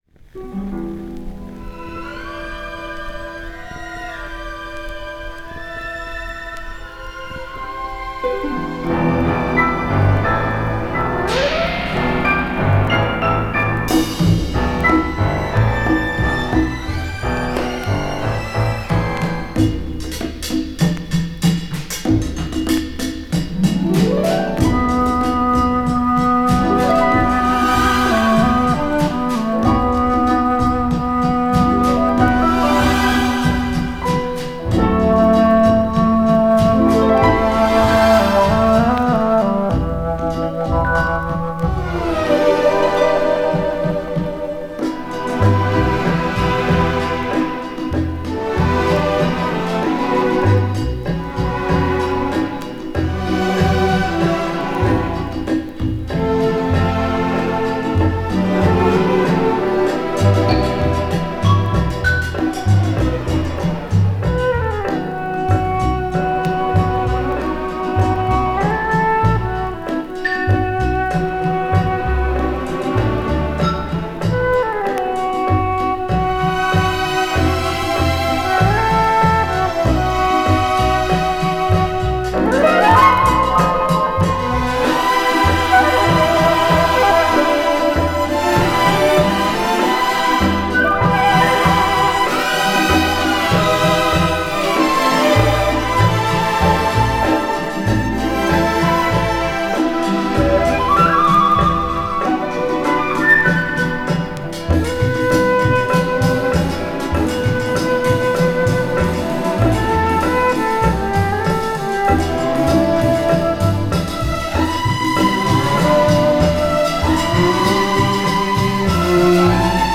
all vinyl Exotica mix